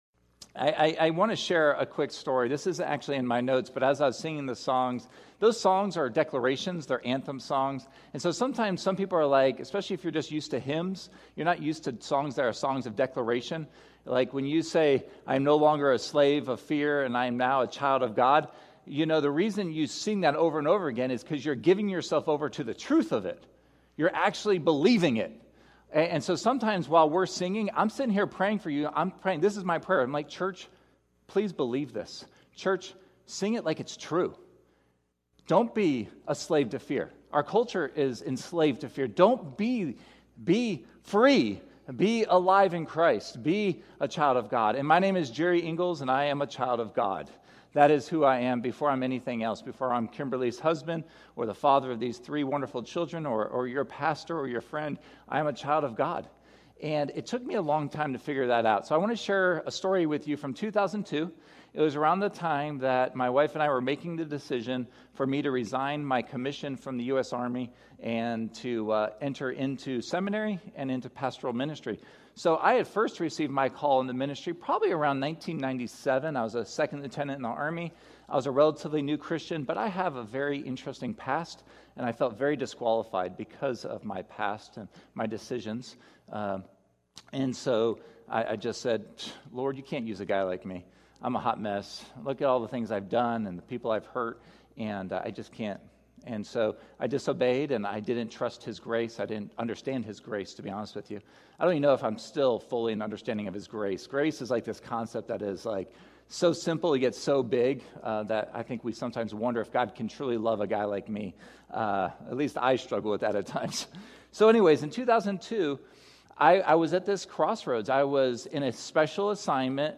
We encourage you to review the notes during the sermon or through the week!